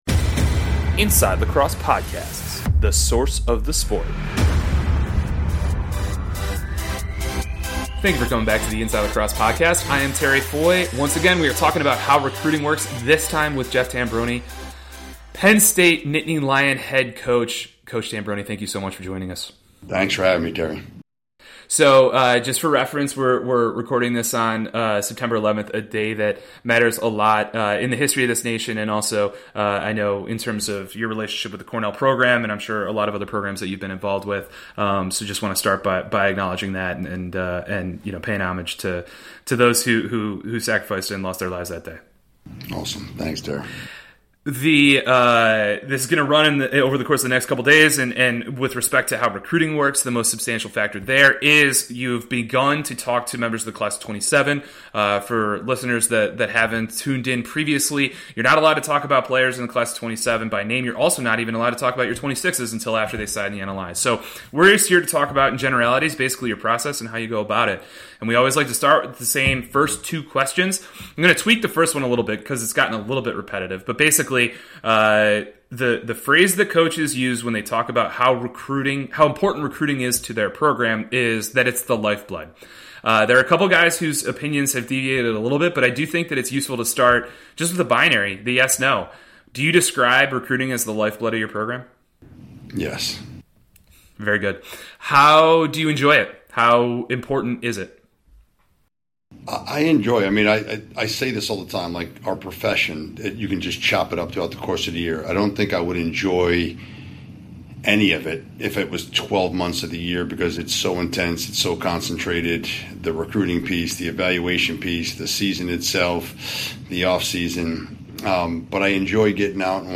he's interviewing a series of DI men's lacrosse coaches about their process